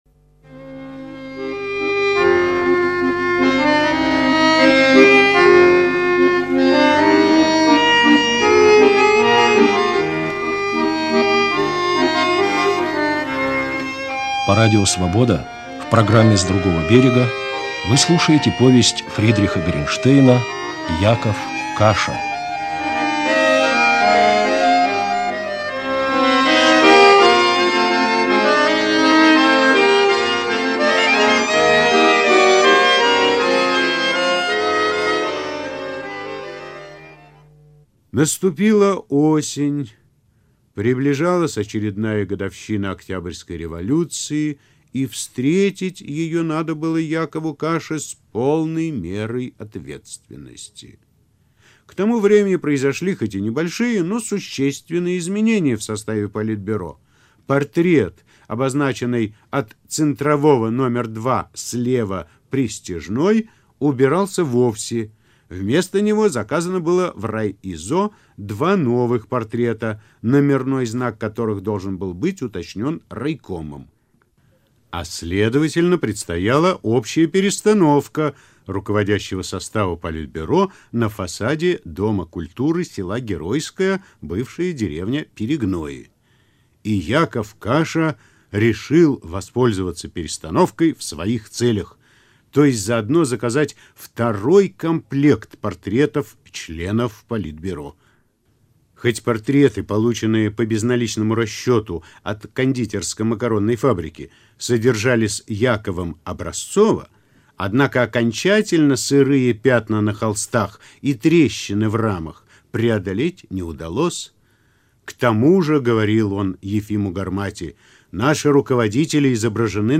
Повесть Ф.Горенштейна "Яков Каша", читает Лев Круглый, часть 4
Повесть Ф.Горенштейна "Яков Каша", читает Лев Круглый, часть 4 (Радио Свобода, архивная запись 1986 г., режиссер - Юлиан Панич)